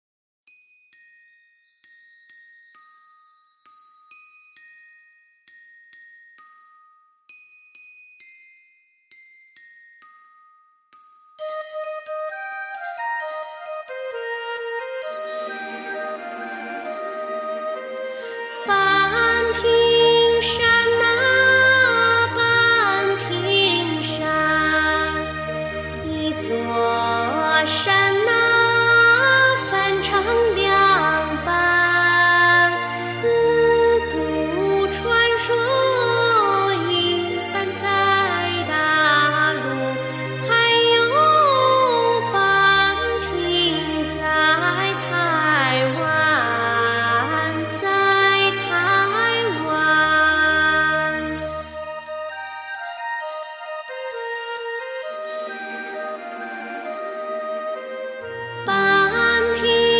范唱：半屏山